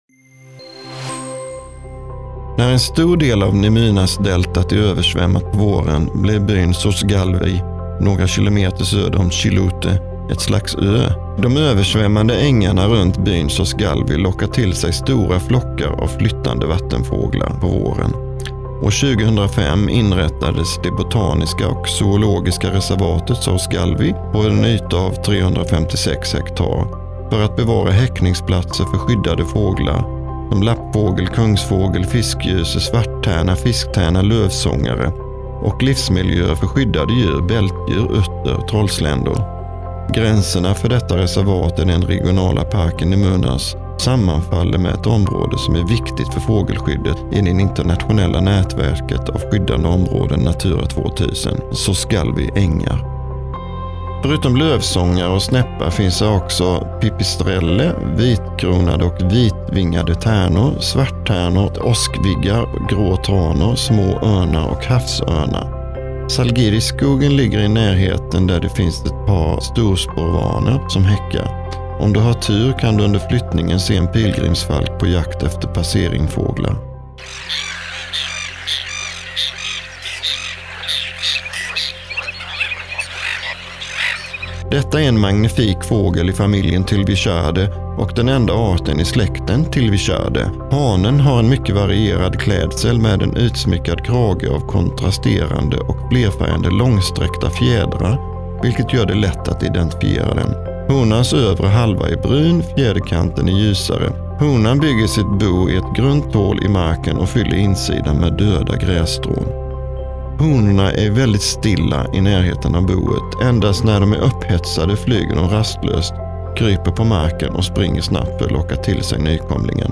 Audiogidas 🇸🇪